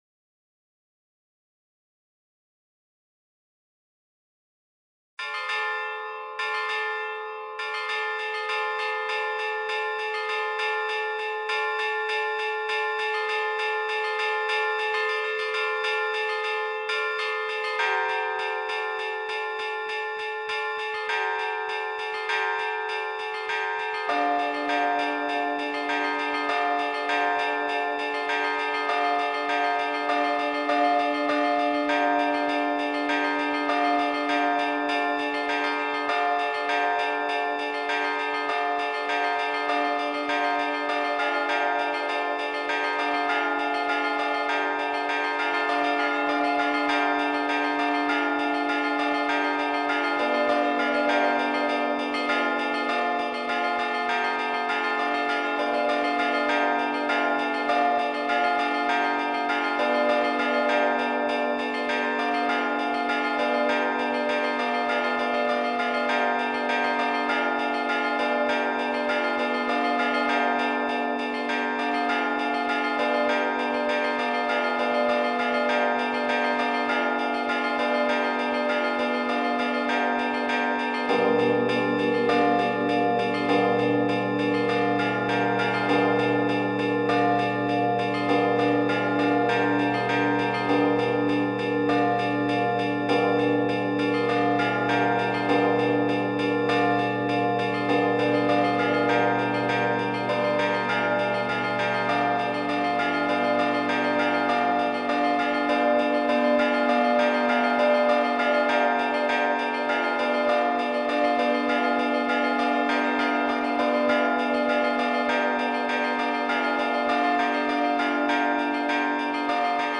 Πως κτυπούν τις καμπάνες οι μοναχοί του Αγίου Όρους
Ακολούθως παραθέτουμε ένα τυπικό παράδειγμα Αγιορειτικής Κωδωνοκρουσίας.
Καμπάνες Αγίου Όρους.mp3
Kampanes Agiou Orous.mp3